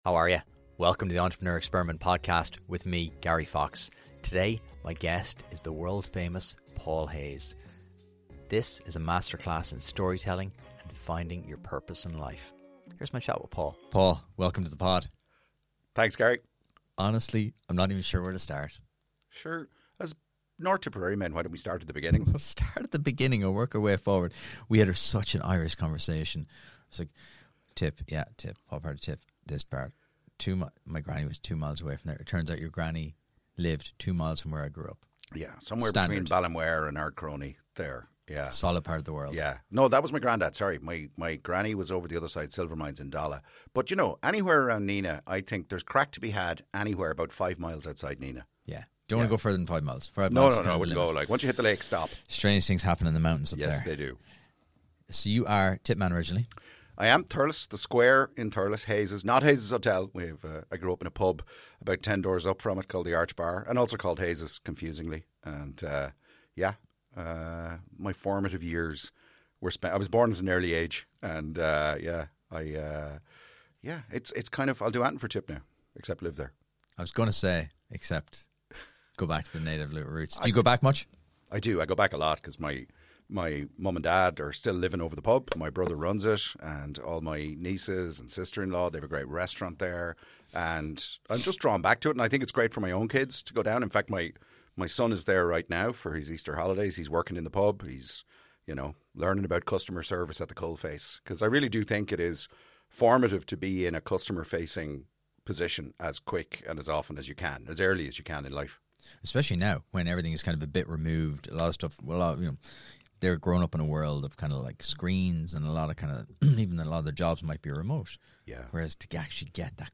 In a wide ranging conversation, we discuss the lessons learned at the intersection of life, business, and the uncharted paths we choose.